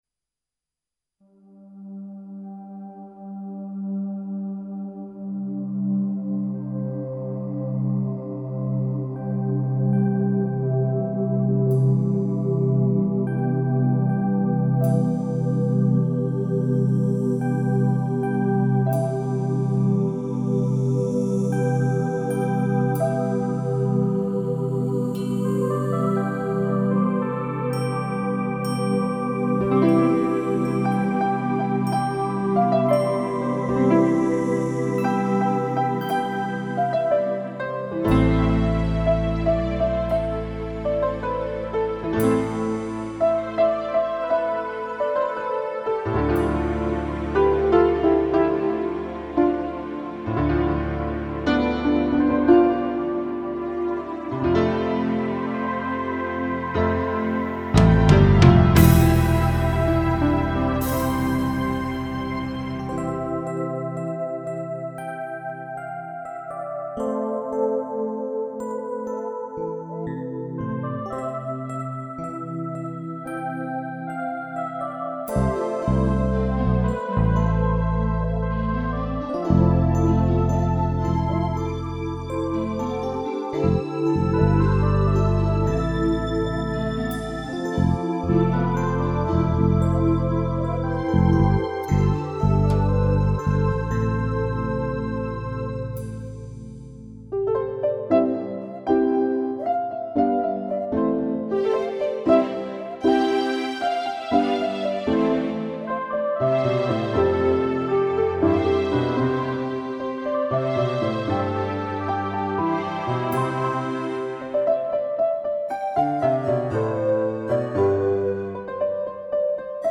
Сборник инструментальных пьес.